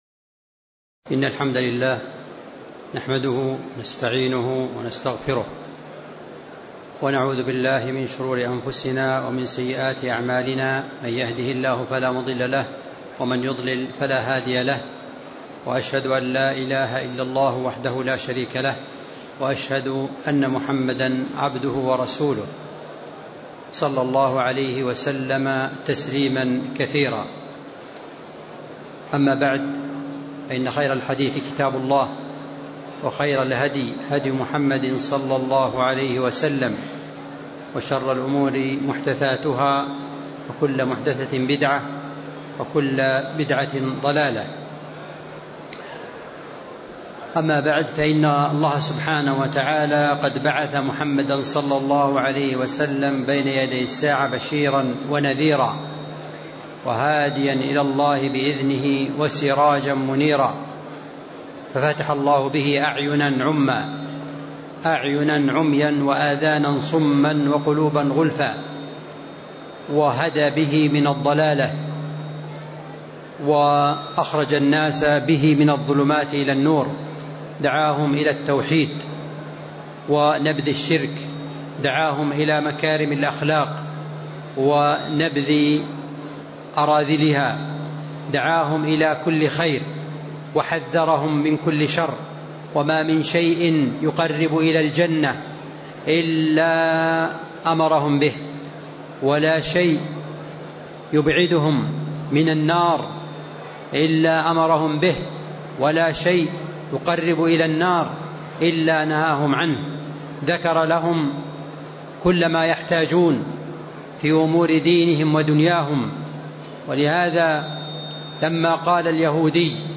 تاريخ النشر ١ جمادى الآخرة ١٤٤٥ المكان: المسجد النبوي الشيخ